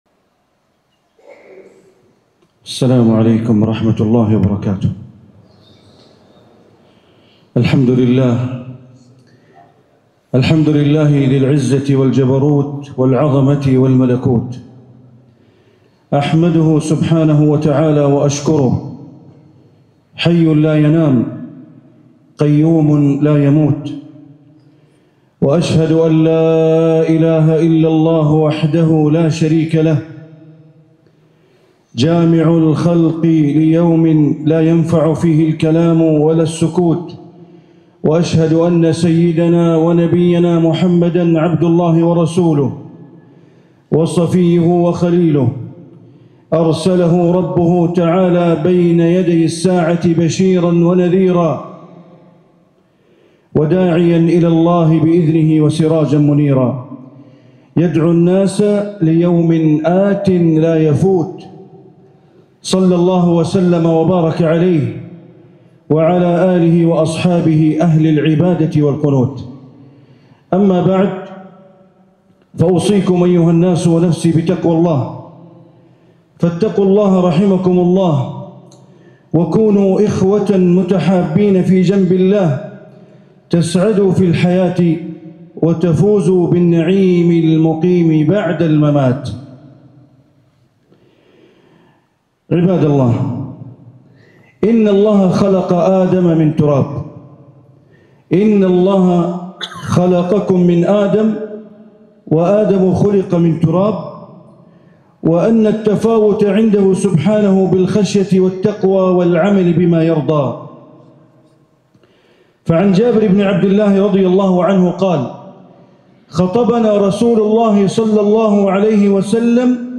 خطبة الجمعة ١١ ذو القعدة ١٤٤٦هـ من كوسوفو > زيارة الشيخ بندر بليلة الى جمهورية كوسوفو > تلاوات وجهود أئمة الحرم المكي خارج الحرم > المزيد - تلاوات الحرمين